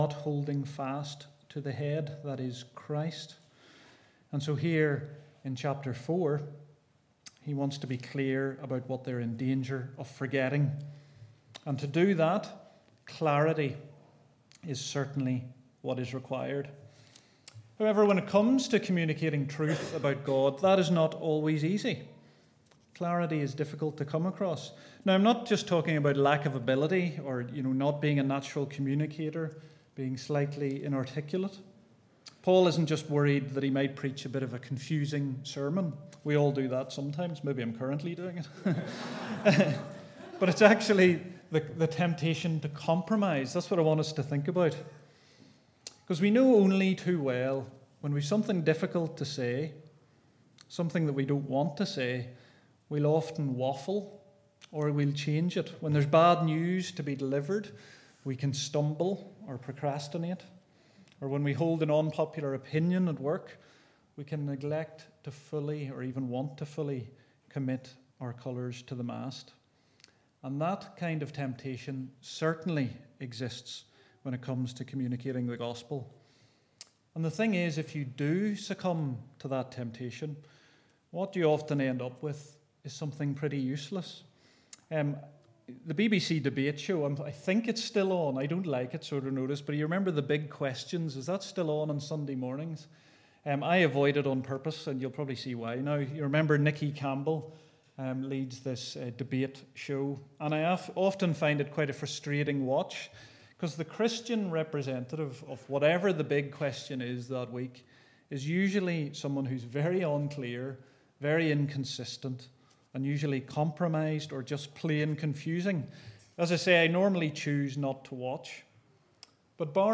Passage: Colossians 4:2-6 Service Type: Weekly Service at 4pm